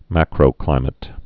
(măkrō-klīmĭt)